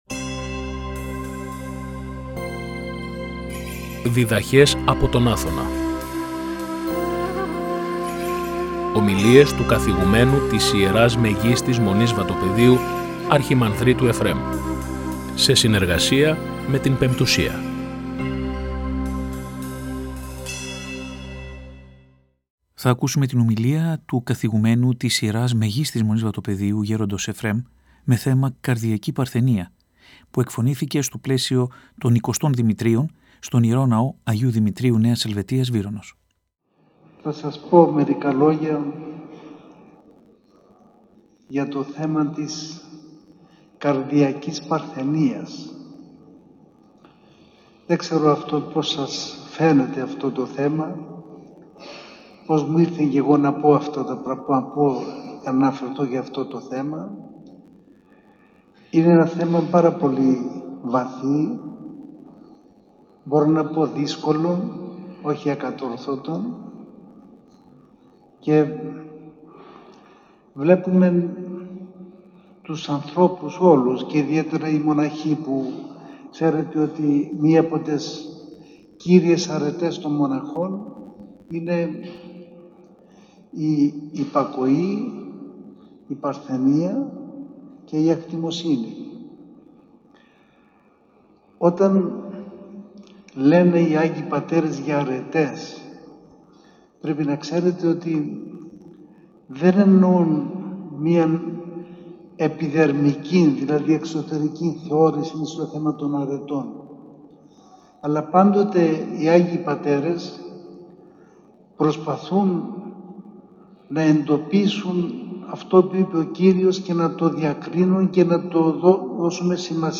Η ομιλία μεταδόθηκε από την εκπομπή «Διδαχές από τον Άθωνα» στη συχνότητα του Ραδιοφωνικού Σταθμού της Πειραϊκής Εκκλησίας και πραγματοποιήθηκε τη Δευτέρα 3 Οκτωβρίου 2022, στον Ιερό Ναό Αγίου Δημητρίου Νέας Ελβετίας Βύρωνος.